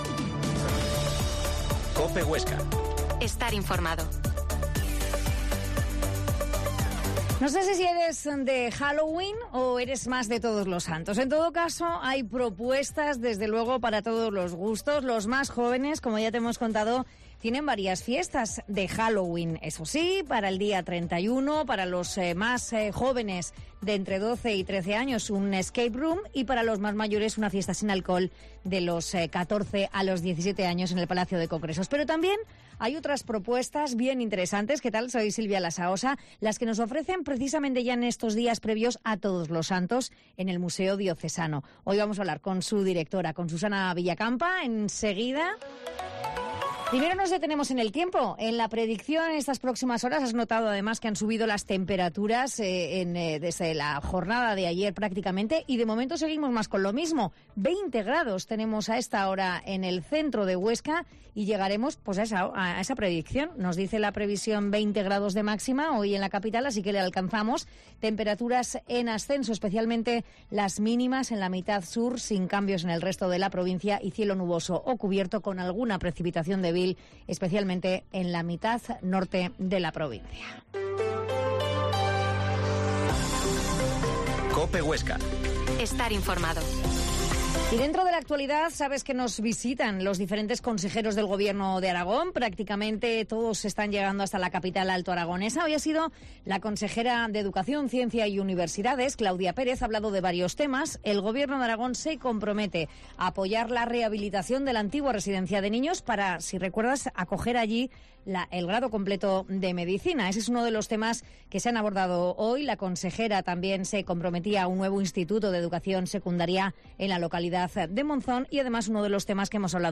Mediodia en COPE Huesca 13.50 Entrevista a la Dtra.